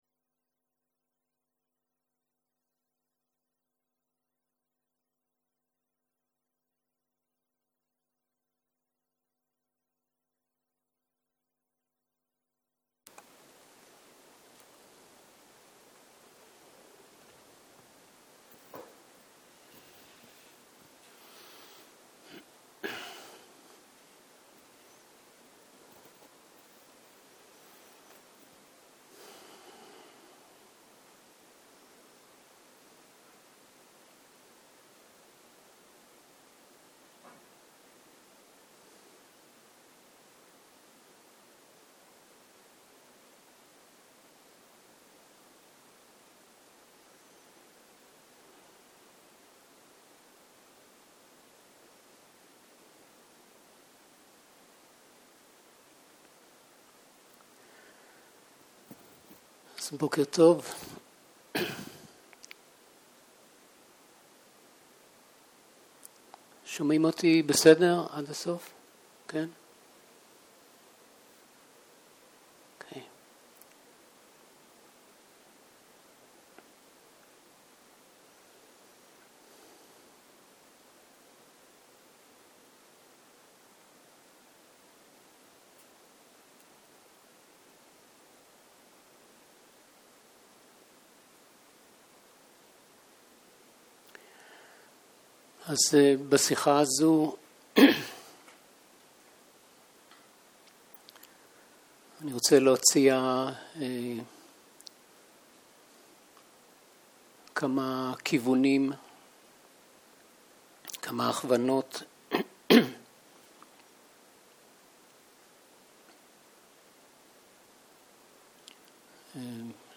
בוקר - הנחיות מדיטציה
שיחת הנחיות למדיטציה שפת ההקלטה